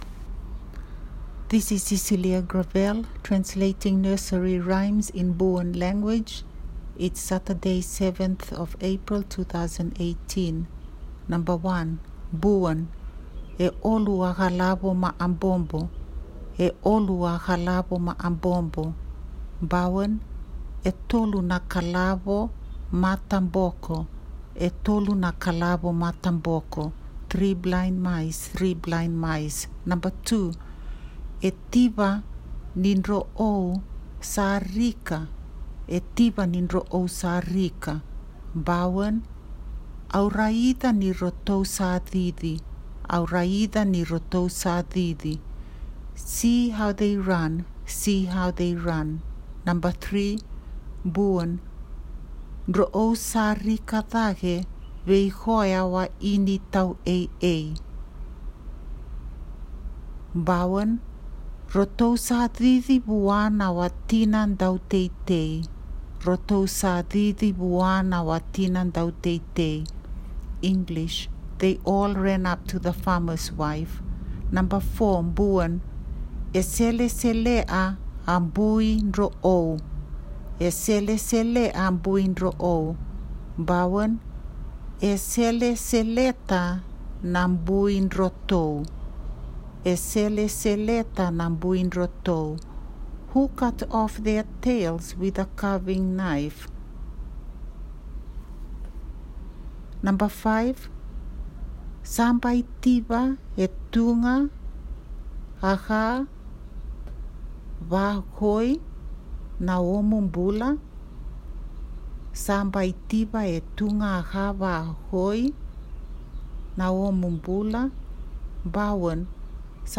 Nursery Rhyme Song